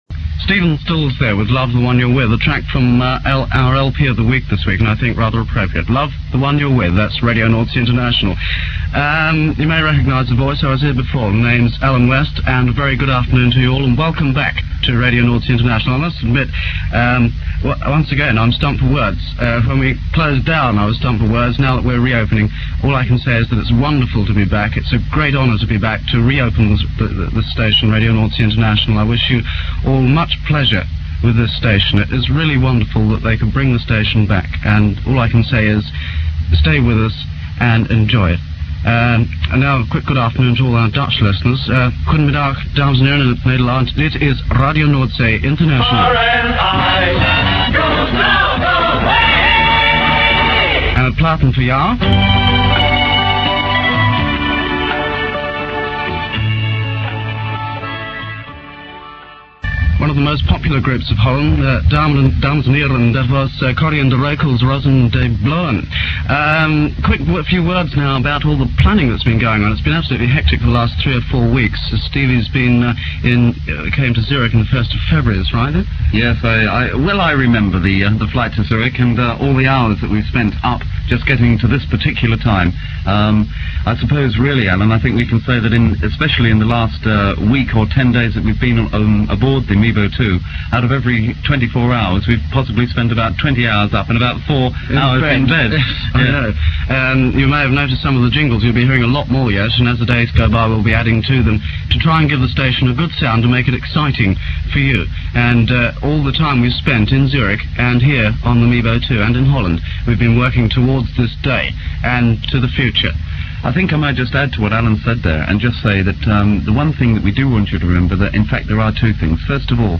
on the opening programme, playing their hitpicks